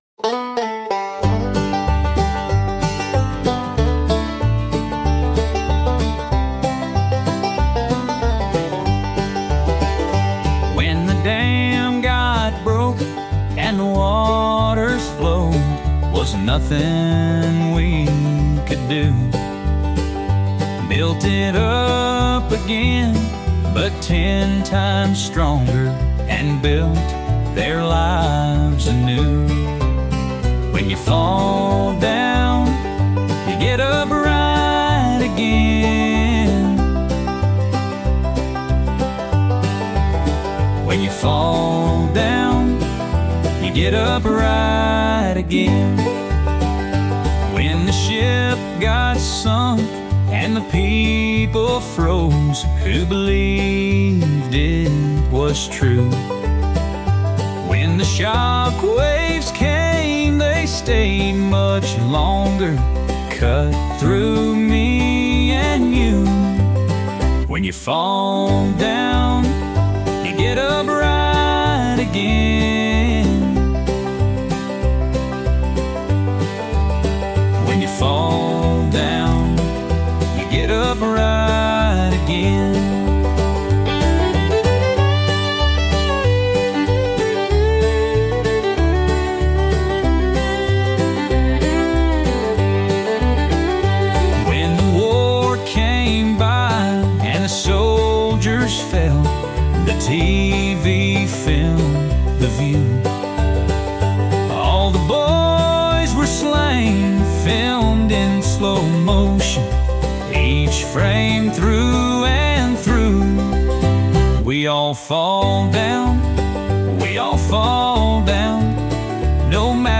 poignant and deeply reflective country song